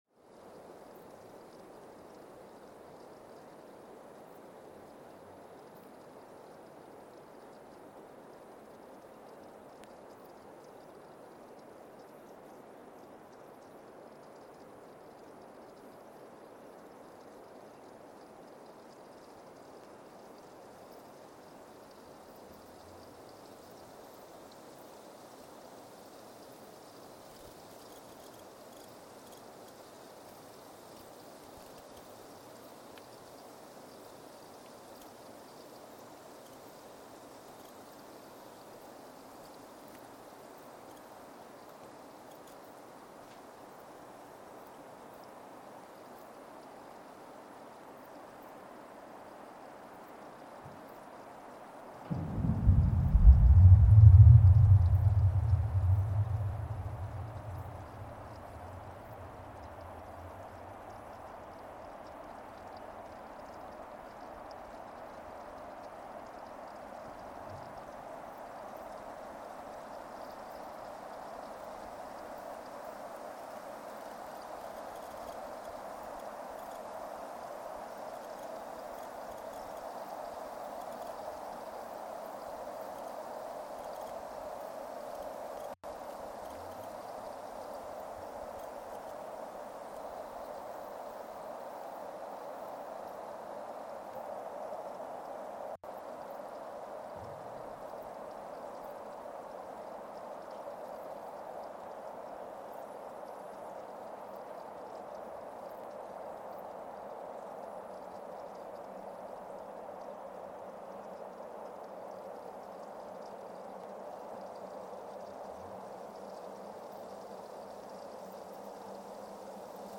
Weston, MA, USA (seismic) archived on December 22, 2024
Sensor : CMG-40T broadband seismometer
Speedup : ×1,800 (transposed up about 11 octaves)
Loop duration (audio) : 05:36 (stereo)
Gain correction : 25dB
SoX post-processing : highpass -2 90 equalizer 300 2q -6 equalizer 400 2q -6 equalizer 90 12q 6